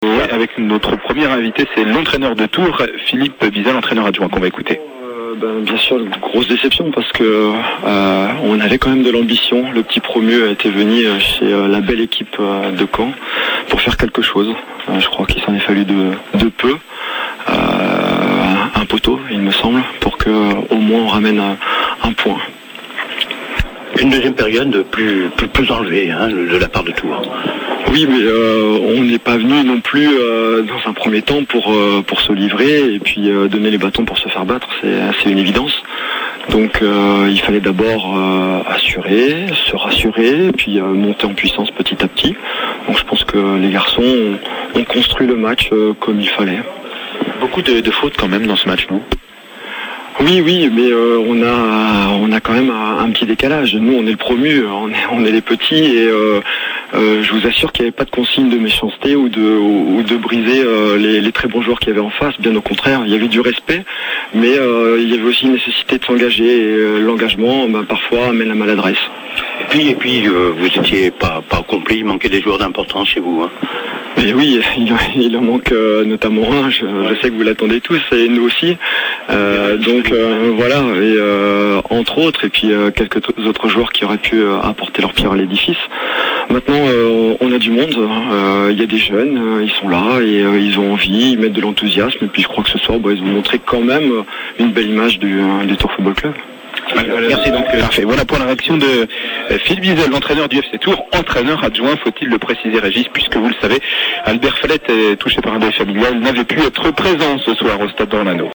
interview complète